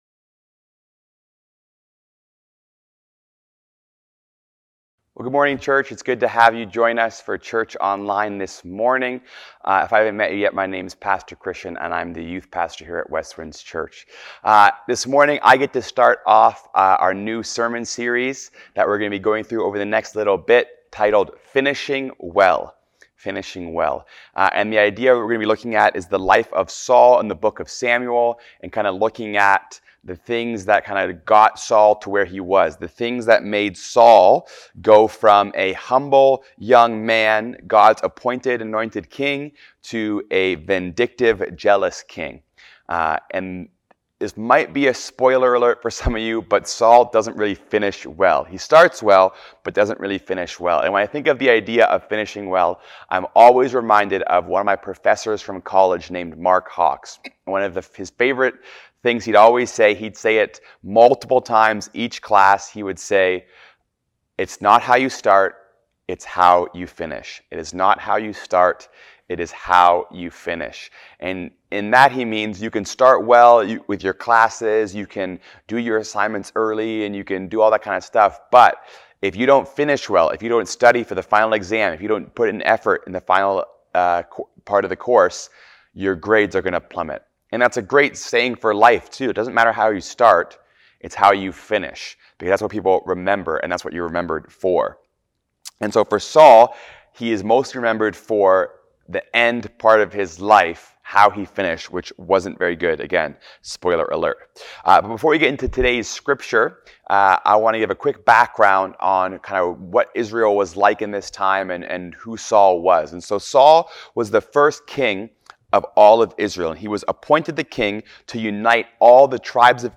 Sermons | Westwinds Community Church